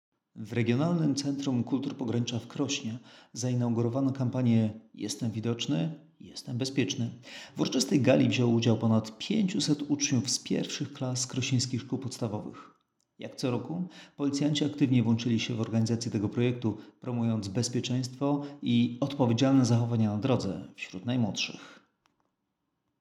Adresowaną dla uczniów szkół podstawowych akcję zorganizowano w sali widowiskowej Regionalnego Centrum Kultur Pogranicza w Krośnie.